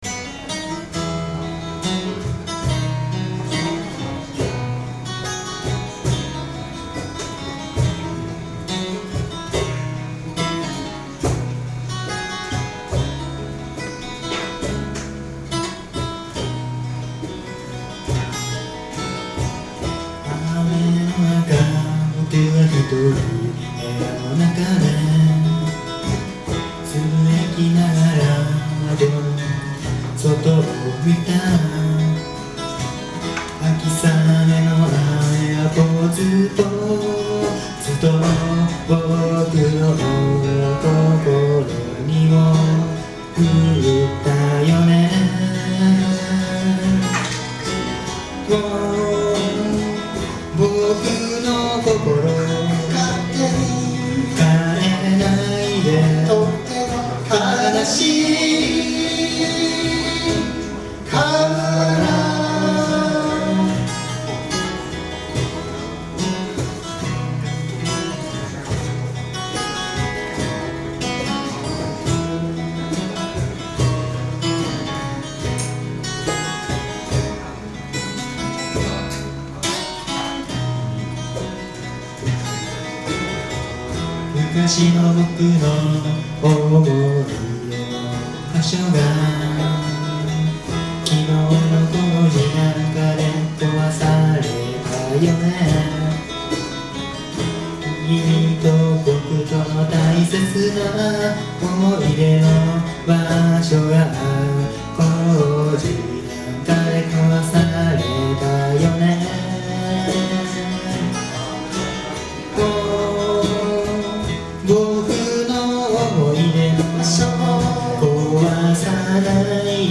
Bluegrass style Folk group
Key of E
その後、コーラスを加えたり、ギターソロを入れたりして、現在のようなスタイルになりました。
録音場所: 風に吹かれて(大森)
ボーカル、ギター
コーラス、ベース
カホン